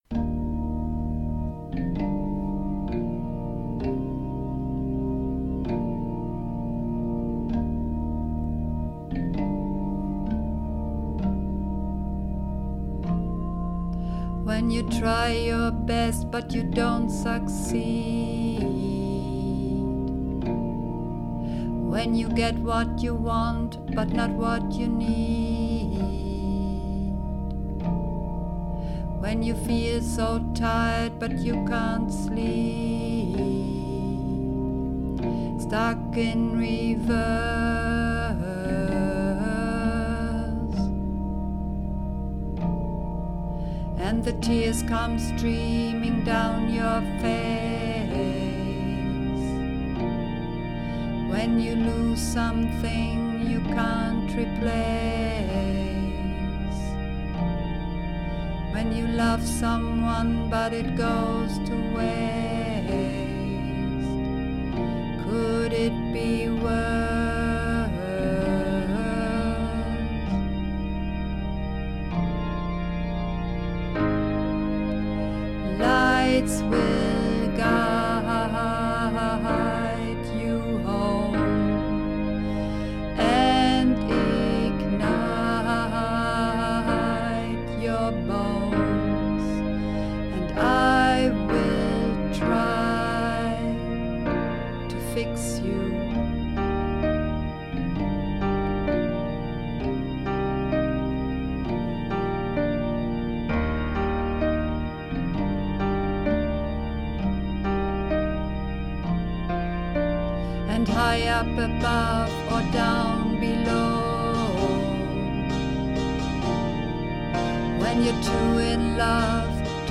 Übungsaufnahmen